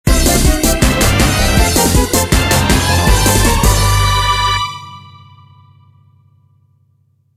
Peach team jingle
Ripped from the ISO